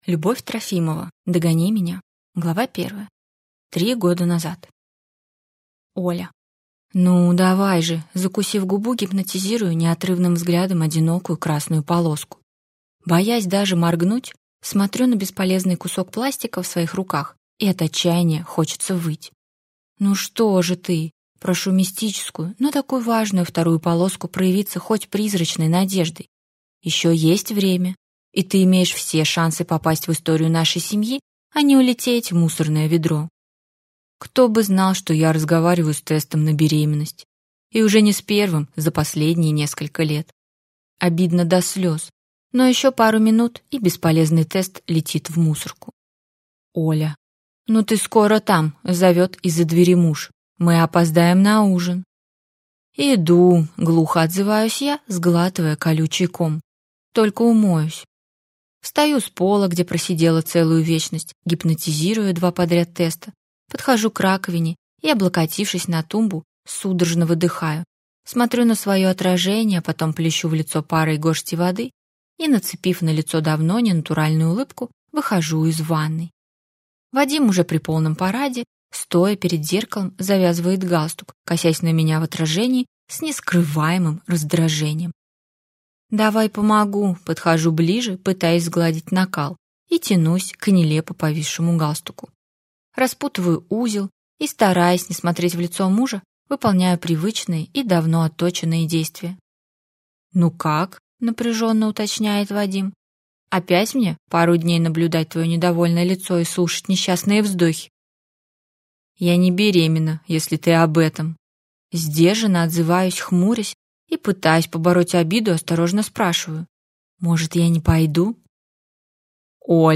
Аудиокнига Догони меня | Библиотека аудиокниг